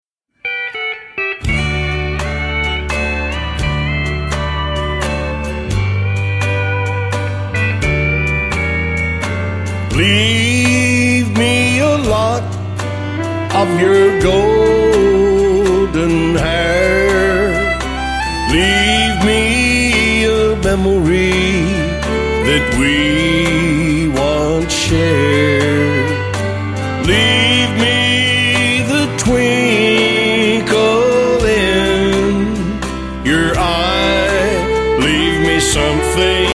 Western Swing